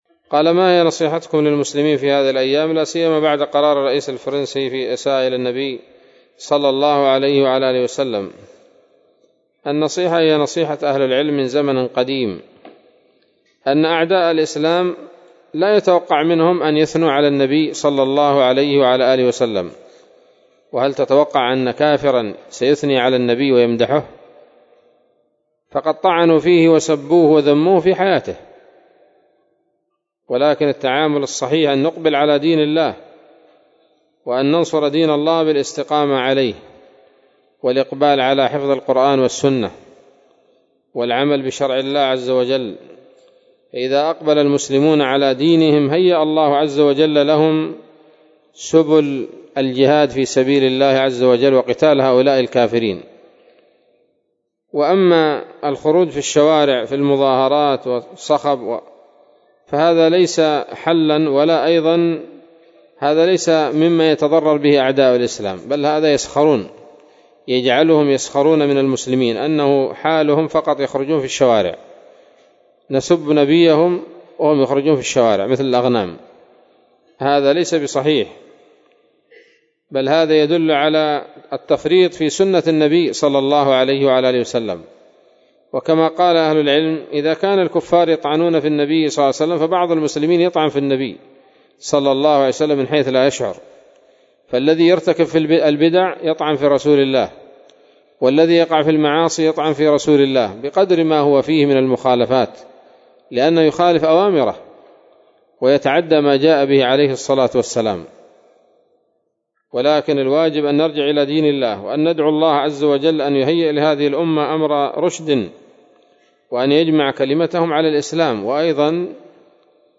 إجابة عن سؤال بعنوان: (( ما نصيحتكم للمسلمين في هذه الأيام لاسيما بعد قرار الرئيس الفرنسي في الإساءة إلى النبي صلى الله عليه وسلم؟ )) عصر الثلاثاء 10 من شهر ربيع أول لعام 1442 هـ، بدار الحديث السلفية بصلاح الدين